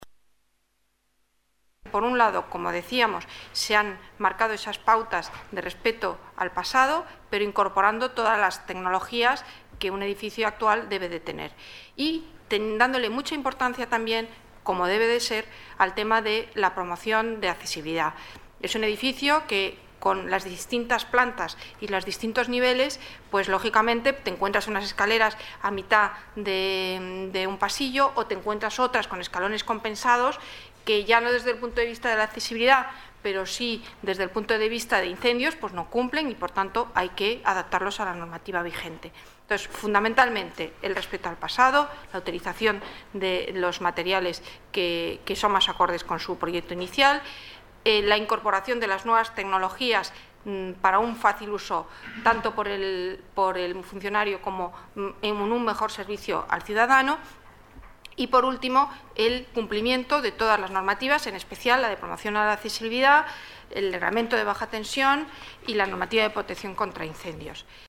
Declaraciones de Amalia Castro, directora general Patrimonio del Área de Hacienda